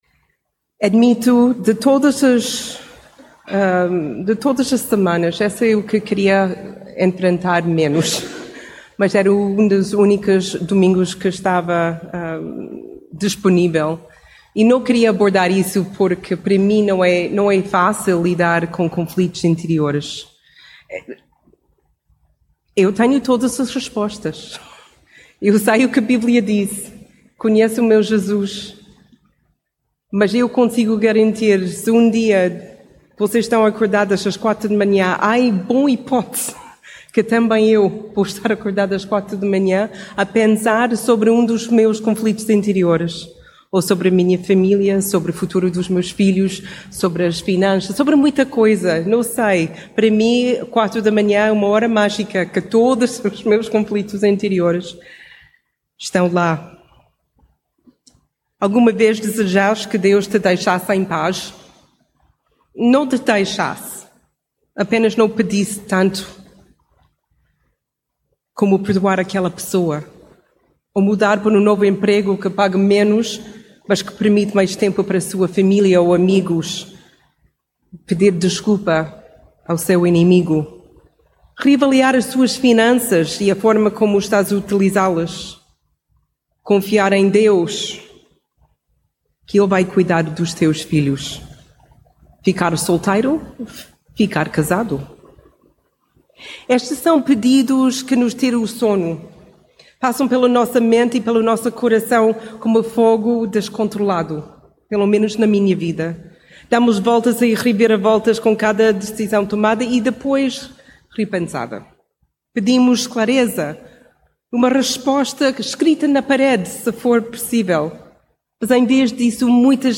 (in)oportuno mensagem bíblica Normalmente, aparecem sorrateiramente às quatro da manhã, quando, de repente, nos vemos acordados.